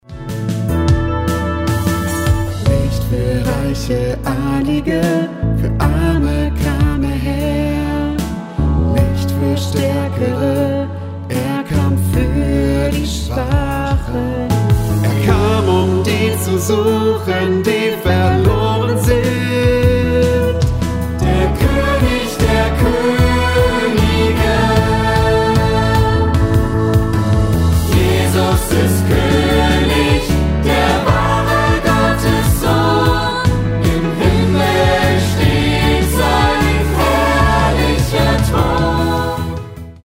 Notation: SATB
Tonart: A
Taktart: 4/4
Tempo: 76 bpm
Kinderlied, Worship, Liedvortrag